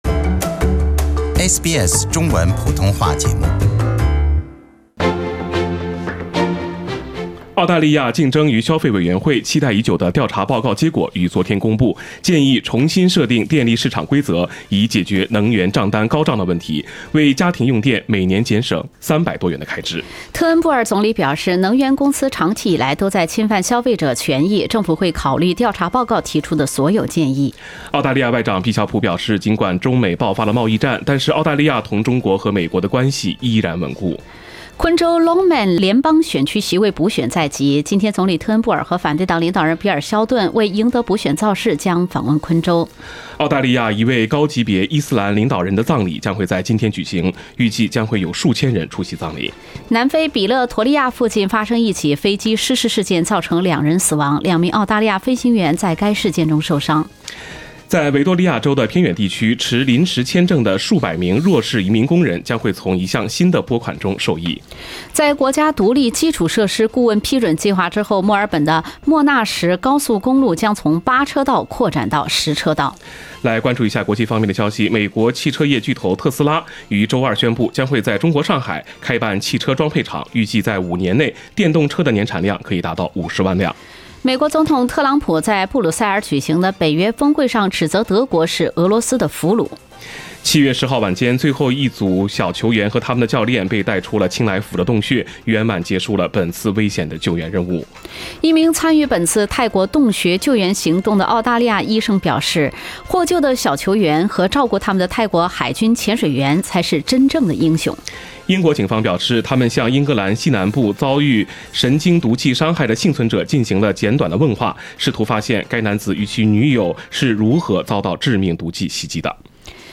SBS 普通話電台
點擊收聽SBS新聞早知道，2分鐘了解國內國際新聞事件。